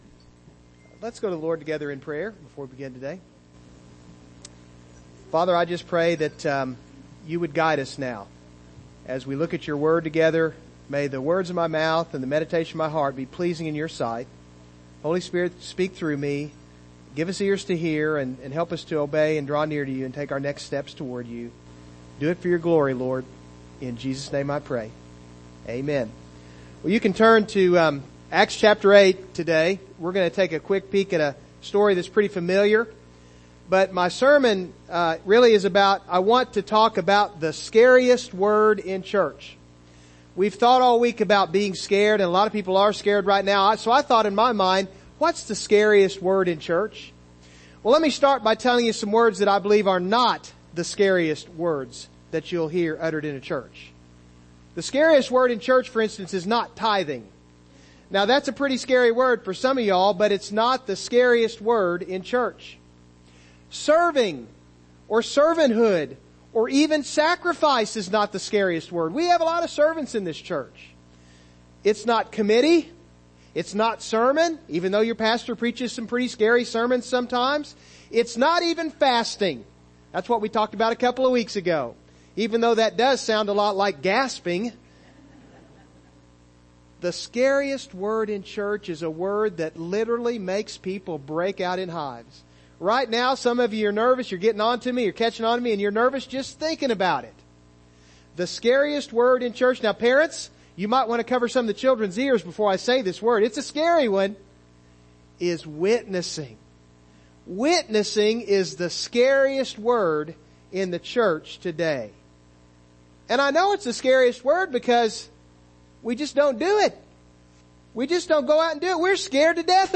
Passage: Acts 8:26-39 Service Type: Morning Service Download Files Notes « Jeremiah: Faithful to the Mission “Growing Stronger” Who’s Your One?